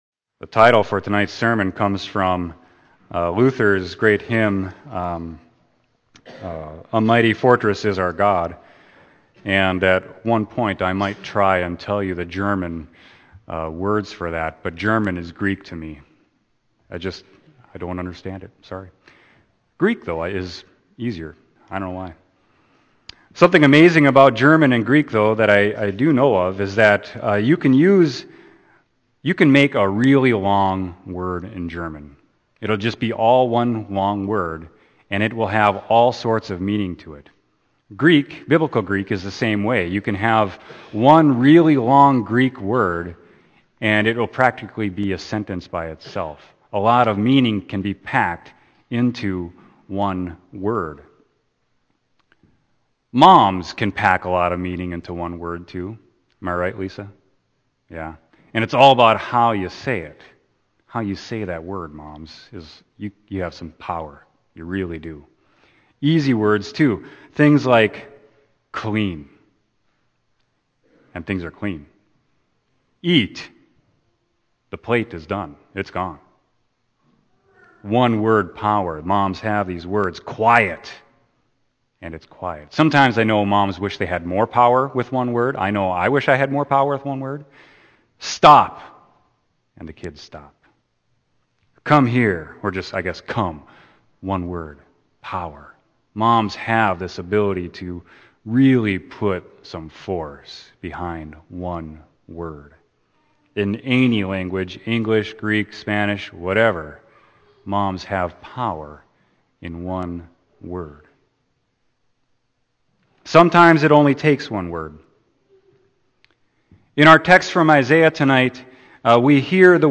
Sermon: Isaiah 49.1-6